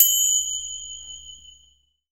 TRIANGLE F-R.WAV